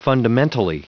Prononciation du mot fundamentally en anglais (fichier audio)
Prononciation du mot : fundamentally